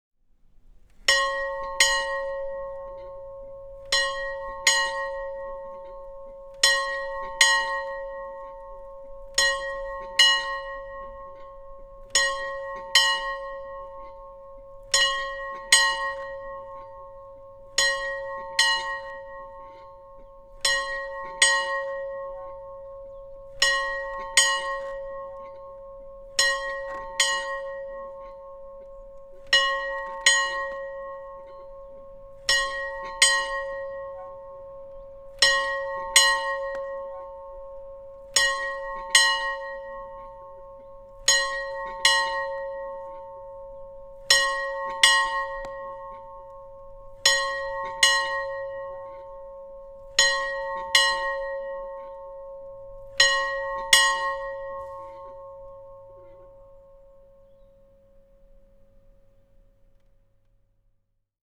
talomuseon-vellikello.wav